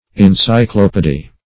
encyclopaedy - definition of encyclopaedy - synonyms, pronunciation, spelling from Free Dictionary